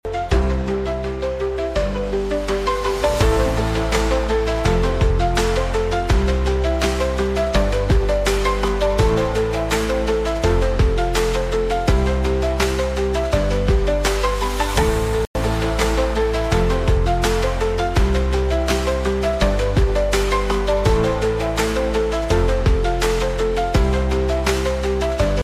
The Moon Hits The Earth Sound Effects Free Download